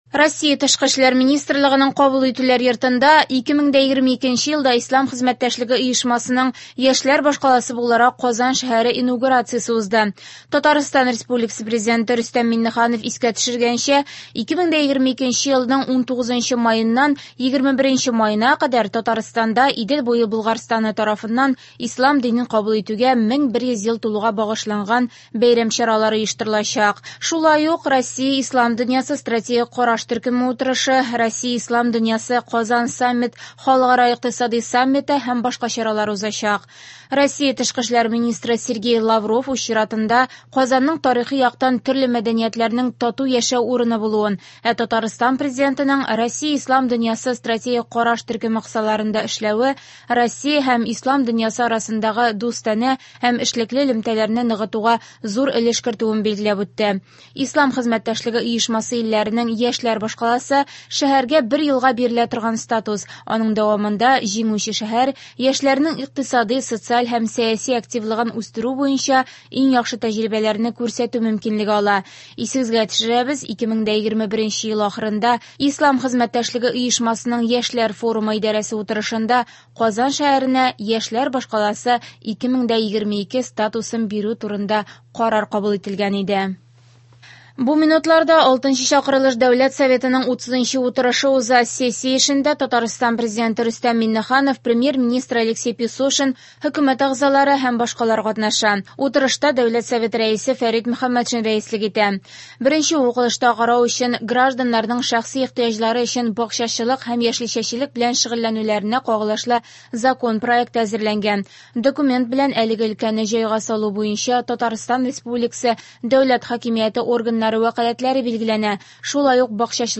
Яңалыклар (16.03.22)